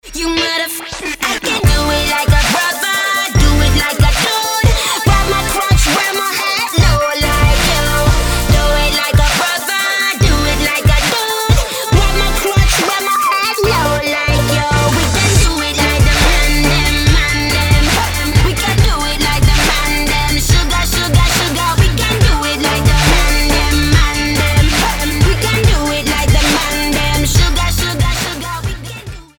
anglická speváčka
sólový singel